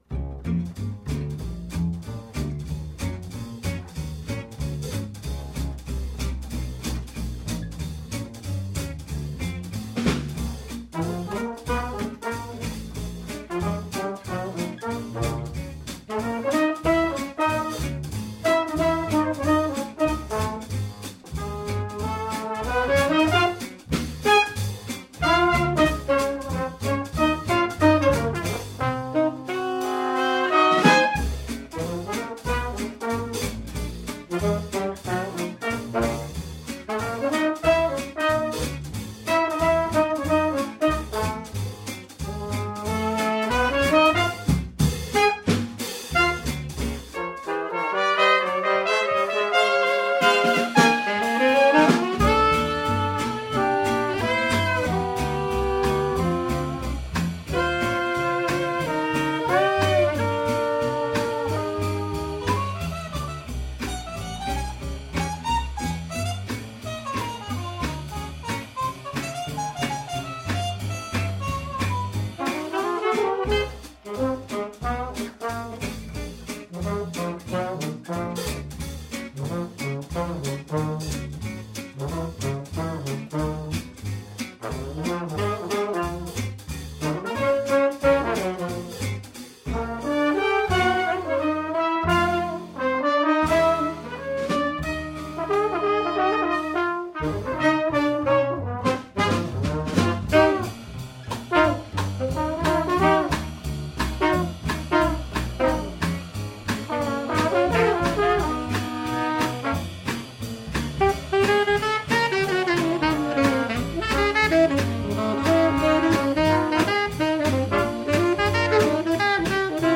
Genre: '20s and '30s Jazz
bass, vocals
saxophone
trombone
drums